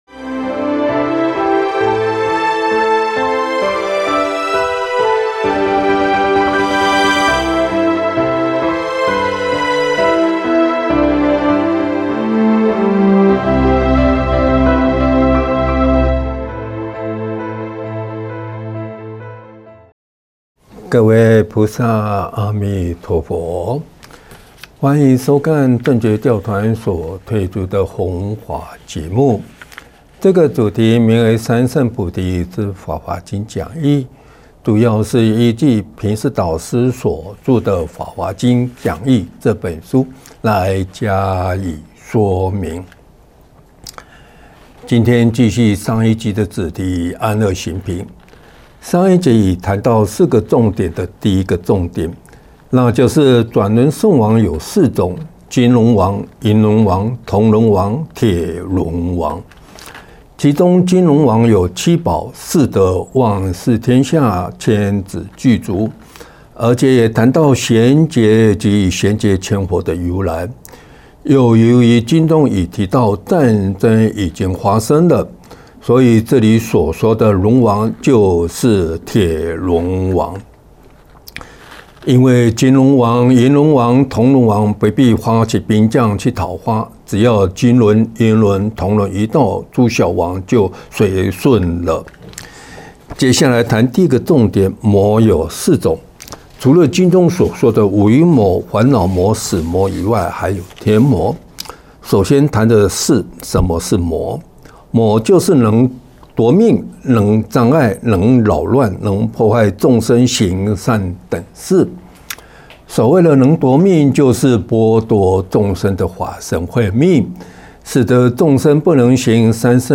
三乘菩提系列讲座，正觉同修会影音，同修会音频，同修会视频